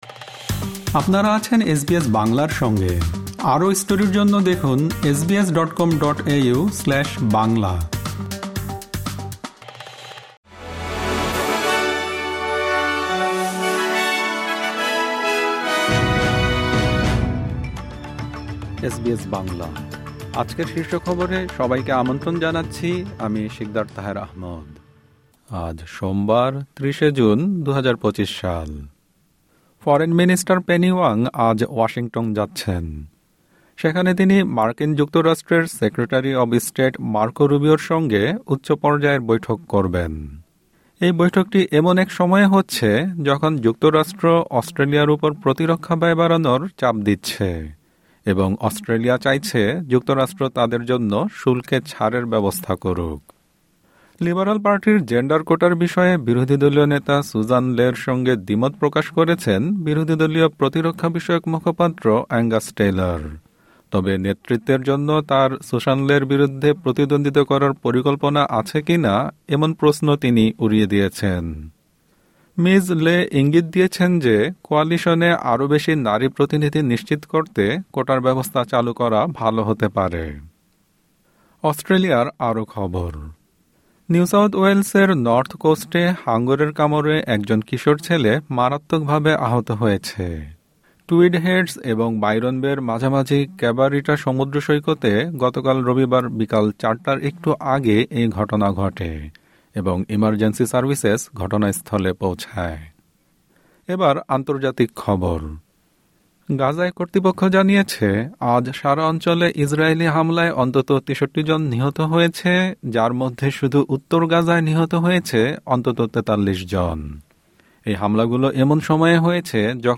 আজকের শীর্ষ খবর ফরেইন মিনিস্টার পেনি ওয়াং আজ ওয়াশিংটনে যাচ্ছেন।